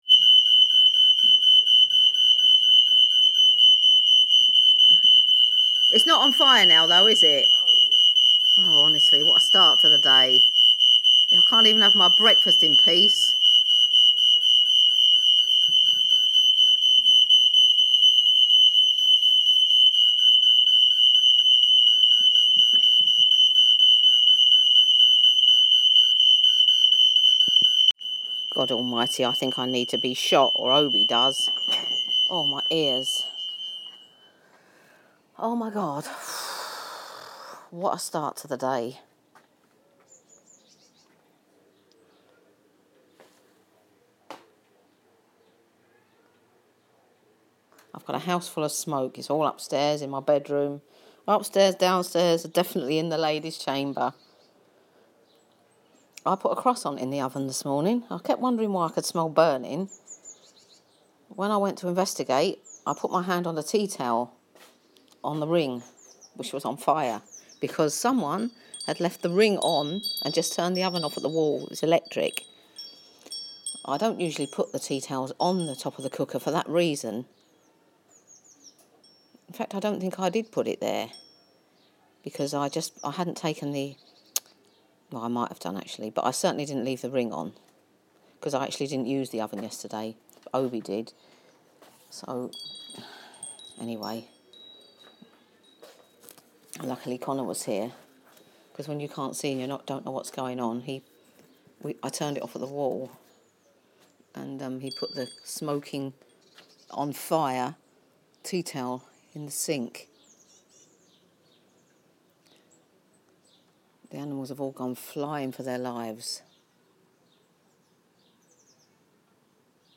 fiery Friday: warning, smoke alarm, very loud